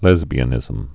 (lĕzbē-ə-nĭzəm)